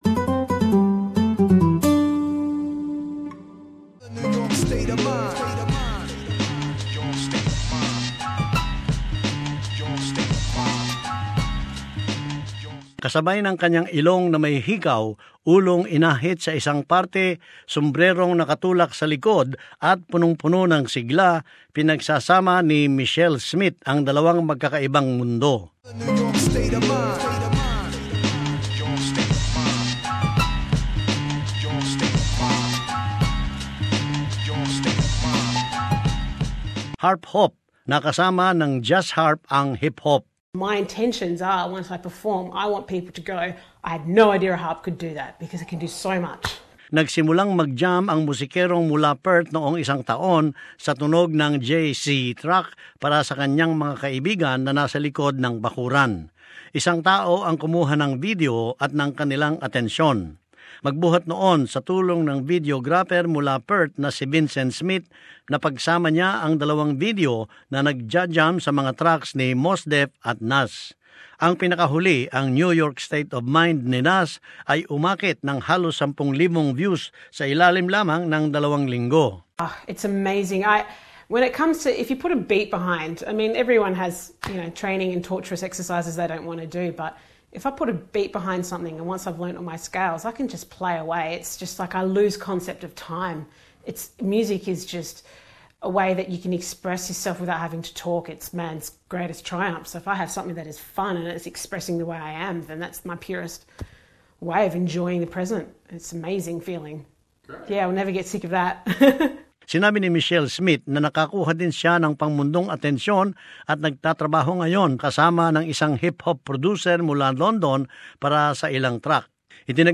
A Perth musician is taking her harp off the stage and onto the street, her gentle strings weaving their way into hip-hop beats.